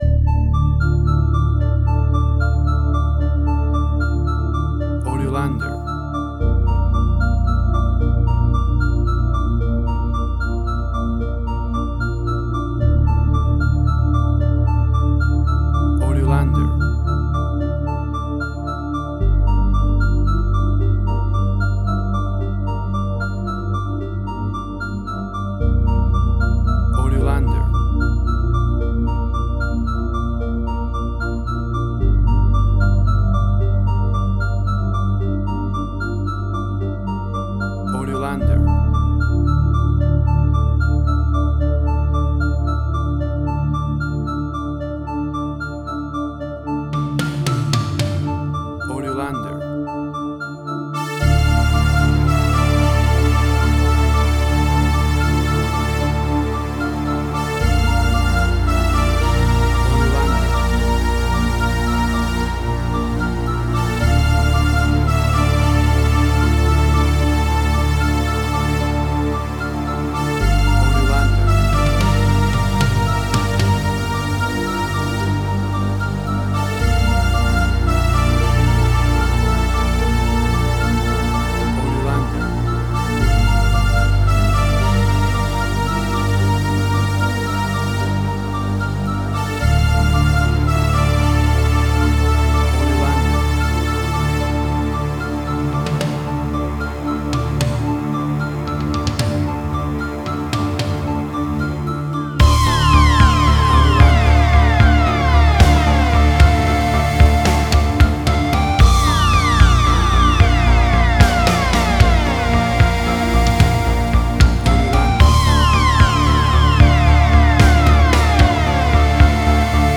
Future Retro Wave Similar Stranger Things New Wave.
Tempo (BPM): 75